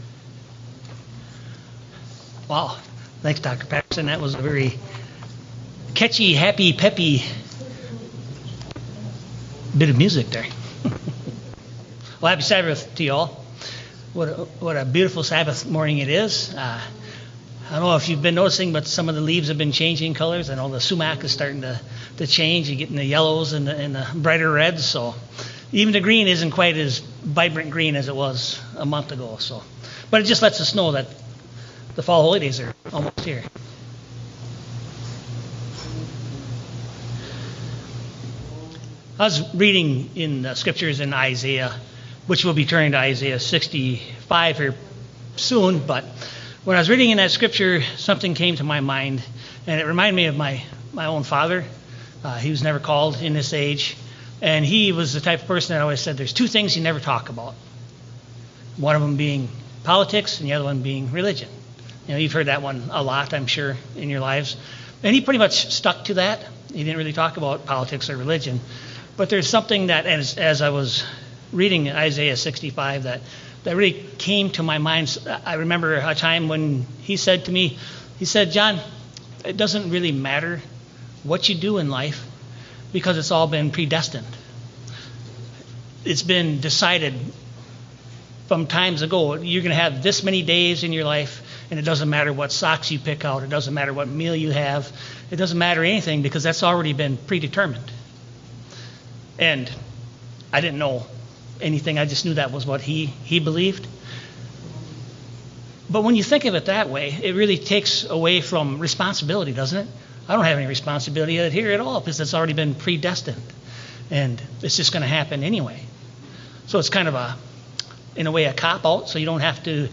In this sermon, we explore the difference between fatalistic “fate” and the true biblical understanding of destiny as God’s purposeful calling for His people. Drawing from Isaiah, Romans, Ephesians, and James, the message shows that while many believe everything in life is fixed and predetermined, Scripture reveals a God who is sovereign yet invites us into a living relationship marked by choice, responsibility, and spiritual growth.